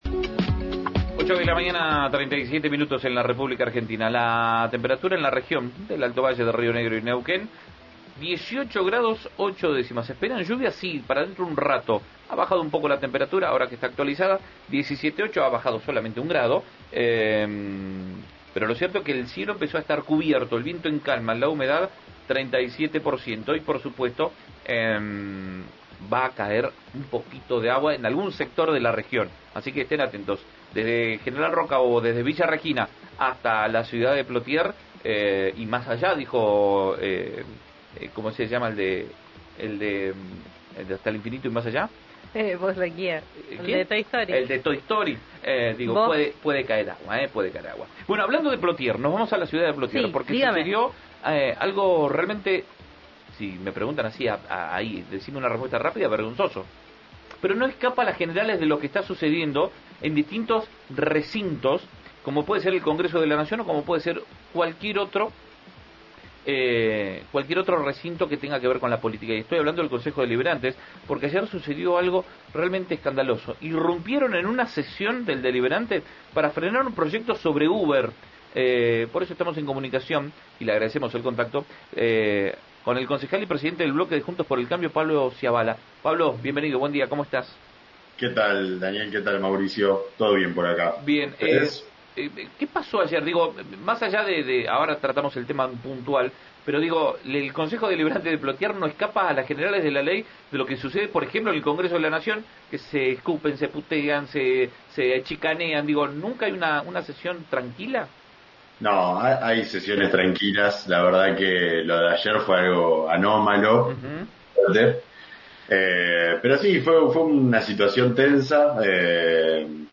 Escuchá a Pablo Scialabba en RIO NEGRO RADIO